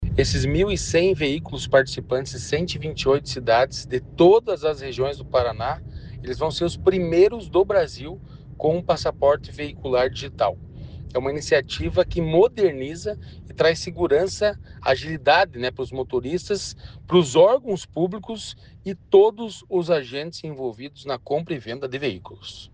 Sonora do diretor-presidente do Detran-PR, Santin Roveda, sobre o projeto-piloto de veículos tokenizados